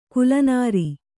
♪ kulanāri